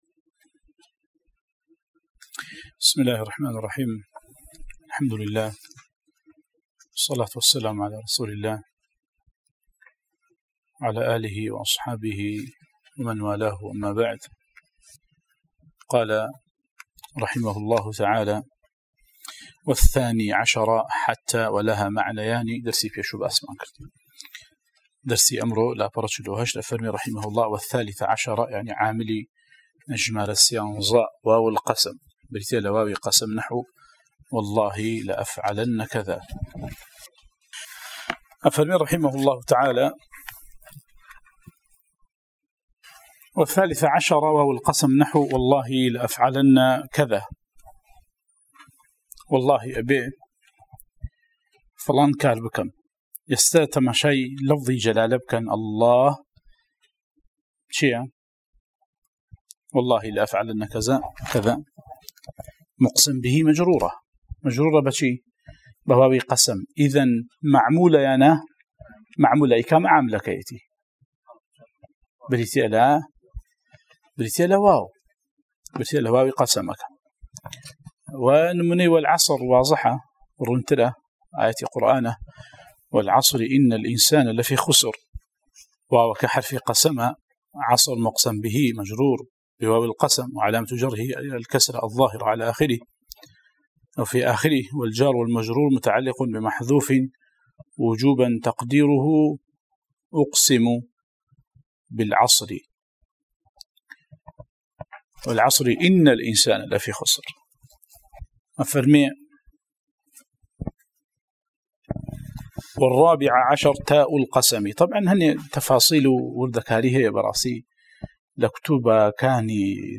06 ـ شەرحی العوامل المائة، (عوامل الجرجانی) (نوێ) وانەی دەنگی: - شرح عوامل المائة (عوامل الجرجاني)